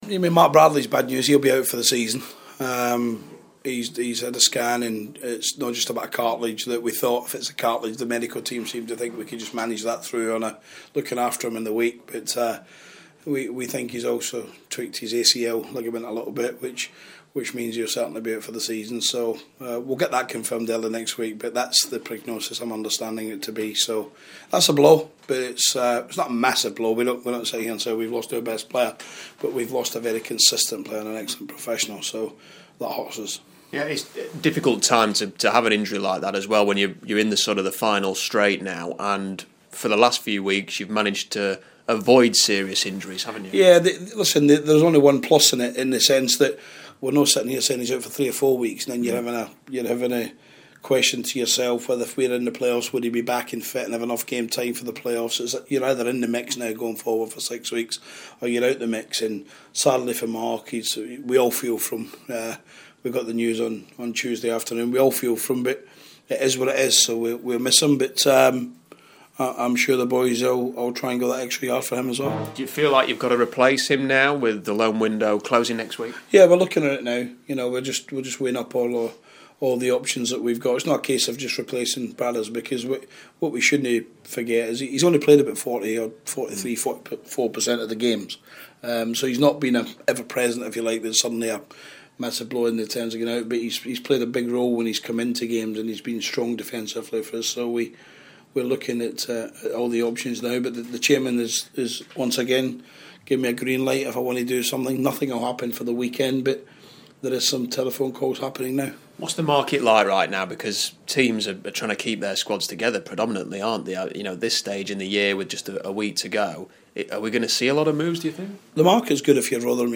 Interview: Rotherham boss Steve Evans on Mark Bradley, 100 games & Peterborough